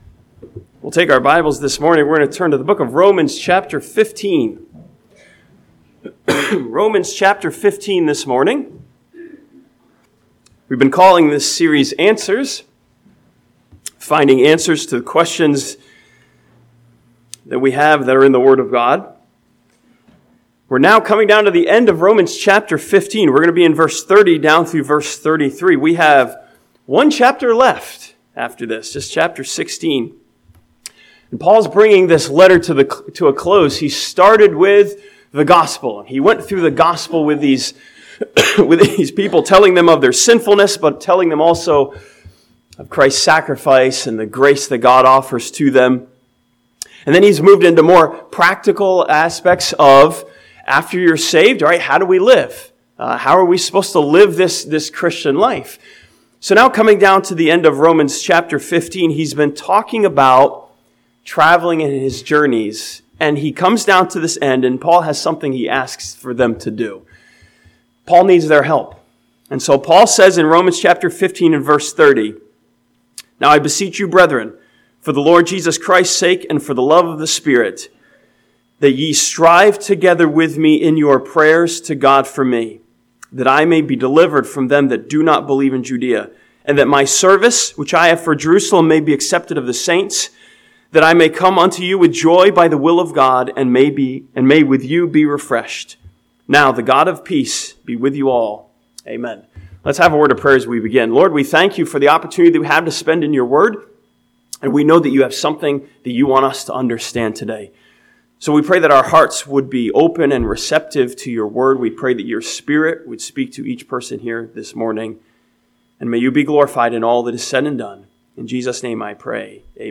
This sermon from Romans chapter 15 asks us a question of support that deals with the subject of praying for one another.